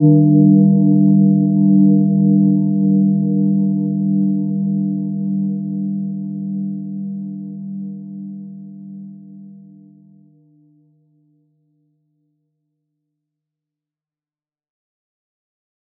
Gentle-Metallic-2-G3-p.wav